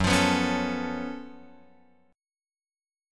F#mM11 chord {2 x 3 4 2 5} chord